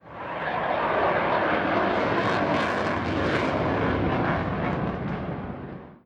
Play, download and share Eurofighter Typhoon original sound button!!!!
eurofighter.mp3